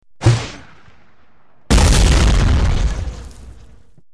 Базука M1 - мощный противотанковый взрыв